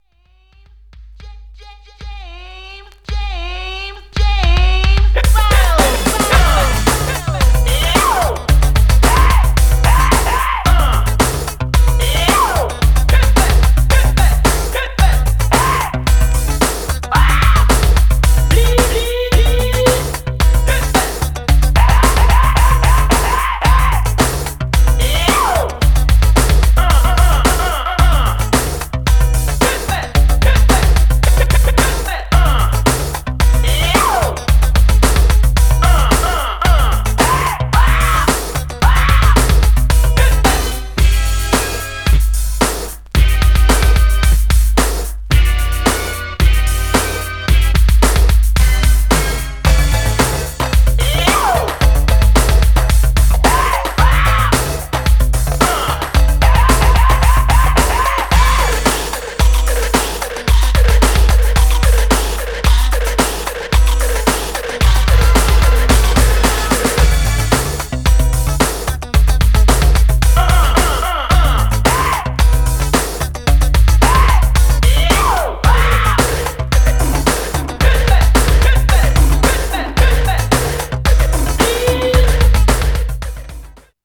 Styl: Hip Hop, Breaks/Breakbeat